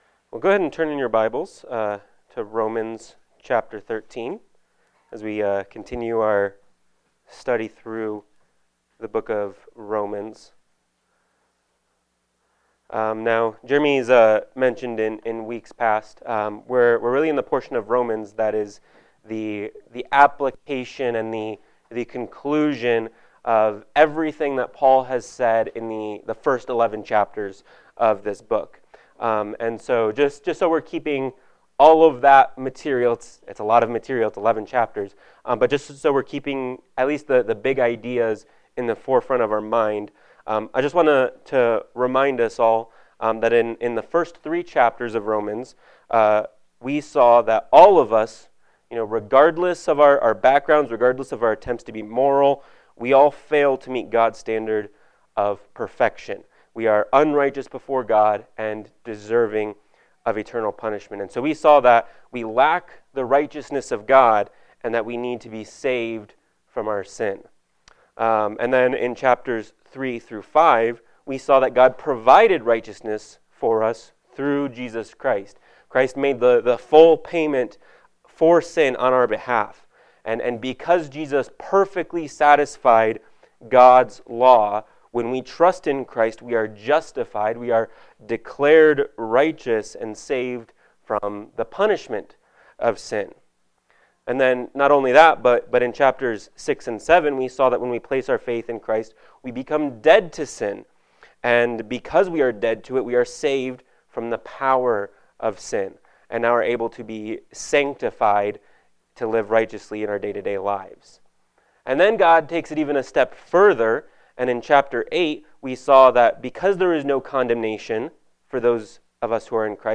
Romans 13:1-7 Service Type: Sunday Morning Worship « Romans 12:14-21